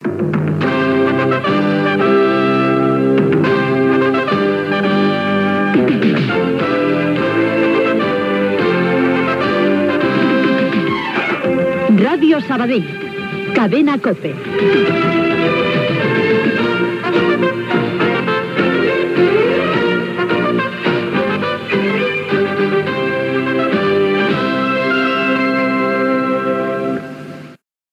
Indicatiu de l' emissora